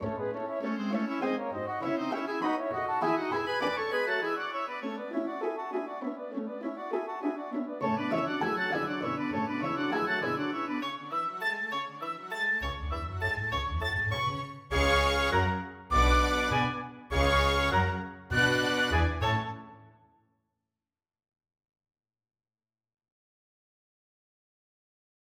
《간결한 옥스퍼드 음악 사전》에서는 이를 “메트릭 패턴에서 일부 비트가 정상 위치보다 앞이나 뒤로 정기적으로 이동하는 것”으로 정의한다.[9] 브람스의 교향곡 2번 피날레에는 막당 네 박자의 지배적인 박자가 깨지는 강력한 악절이 등장한다.